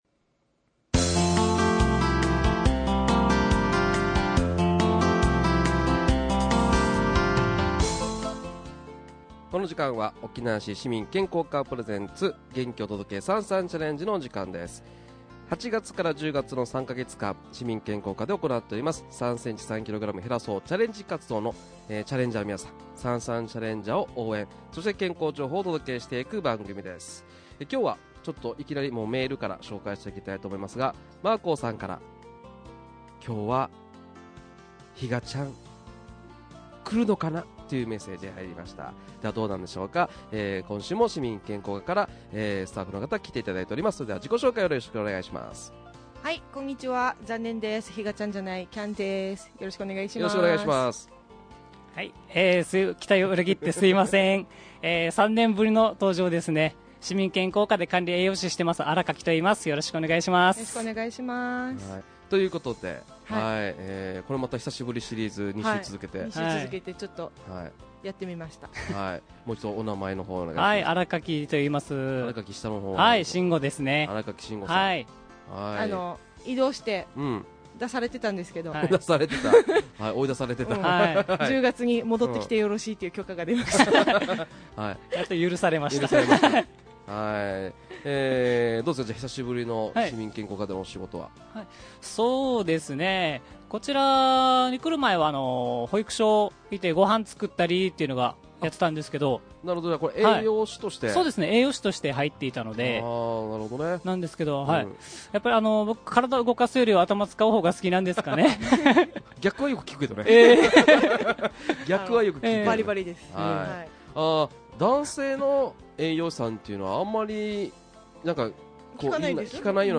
沖縄市 市民健康課が、平成24年8月〜10月の期間実施している 「3㎝3㎏減らそうチャレンジ活動」 その期間中、33チャレンジャーの応援そして生活に密着した健康情報を、市民健康課の専門スタッフが発信していく番組 「元気お届け！